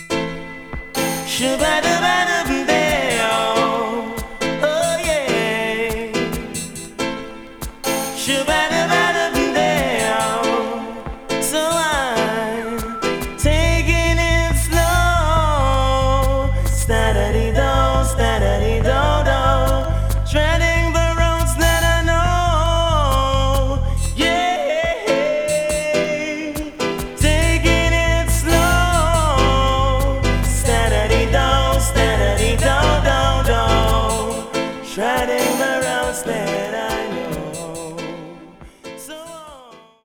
Genre: Dancehall, Reggae